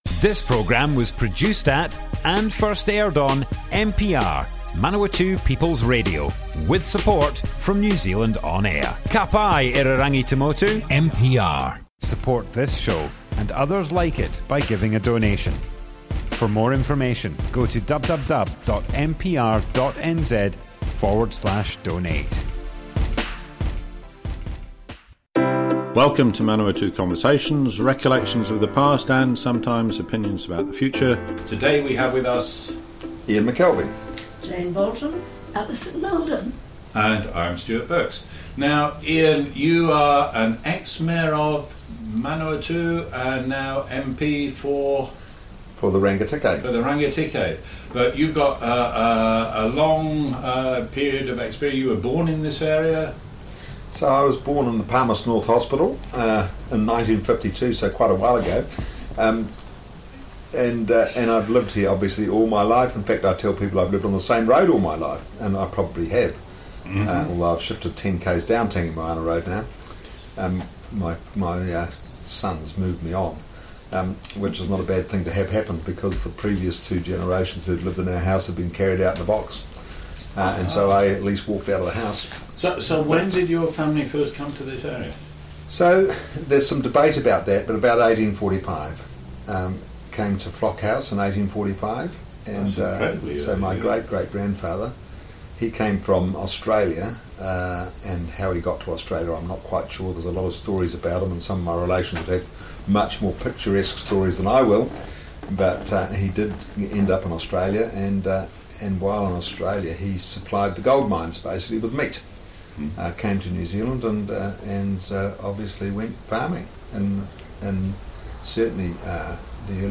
00:00 of 00:00 Add to a set Other Sets Description Comments Ian McKelvey Part 1, early life - Manawatu Conversations More Info → Description Broadcast on Manawatu people's Radio, 6th April 2021.
oral history